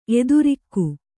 ♪ edurikku